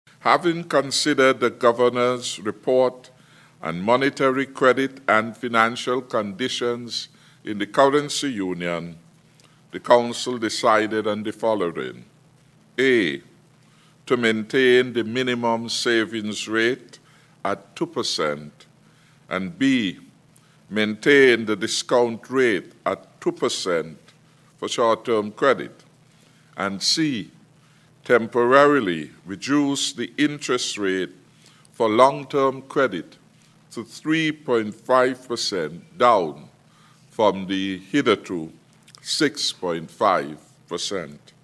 He was speaking during the Presentation of Communiqué in the 98th Meeting of the ECCB Monetary Council Media Conference on Friday, February 12th, 2021.